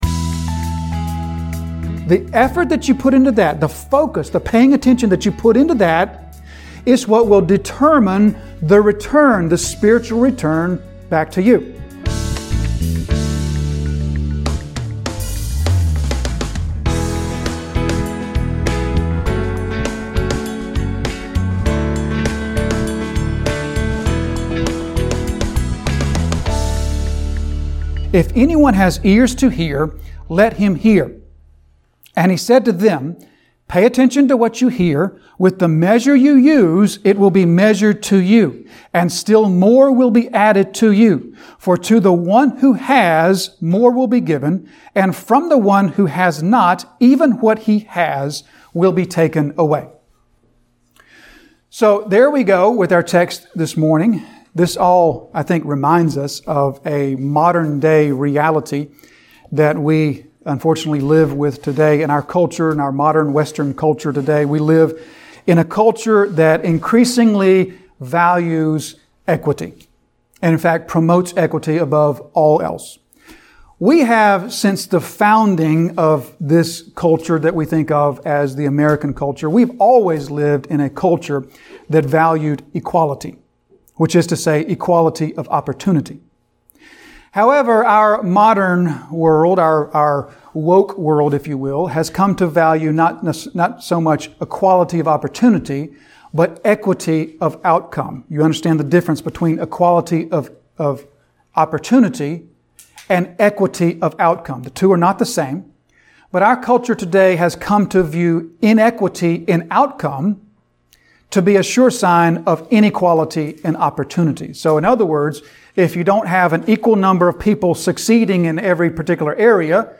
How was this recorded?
An expository sermon delivered at Disciples Fellowship Church, Jonesville, NC.